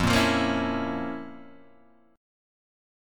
F Major 7th Suspended 4th Sharp 5th